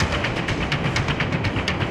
RI_DelayStack_125-02.wav